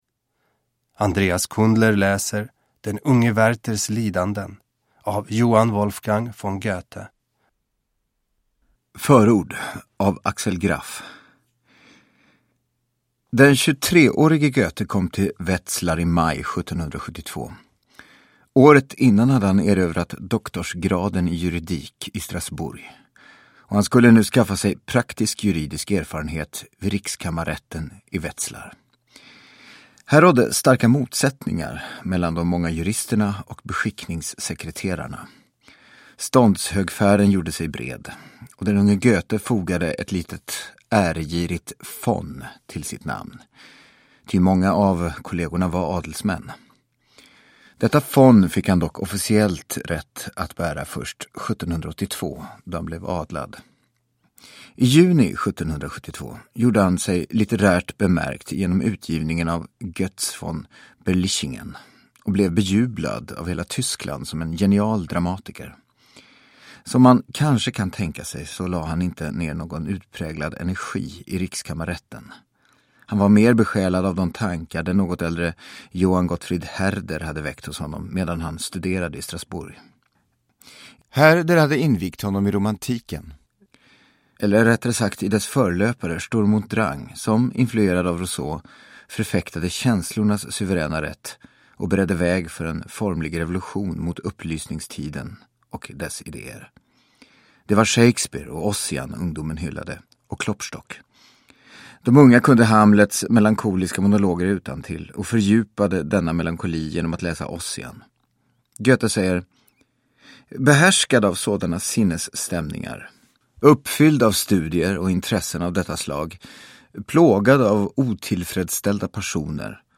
Den unge Werthers lidanden / Ljudbok